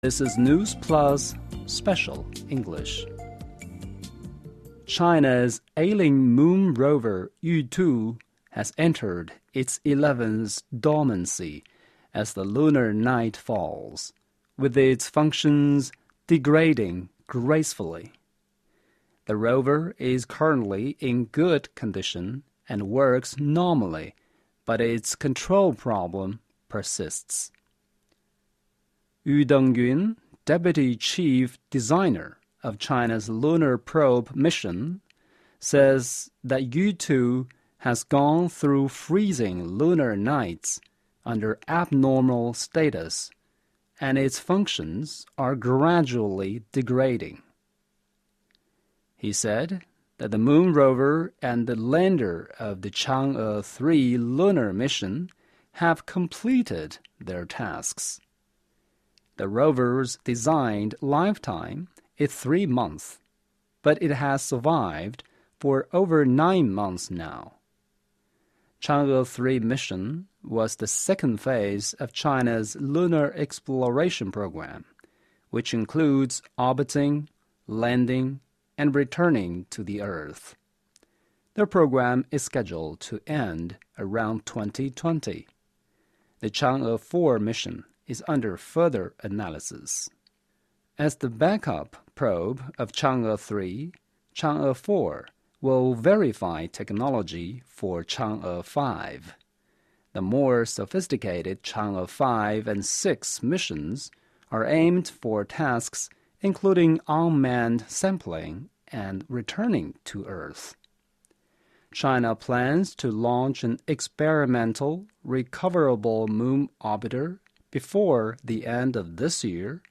News Plus慢速英语:玉兔号进入第11次月夜休眠 经济衰退与生育率降低有关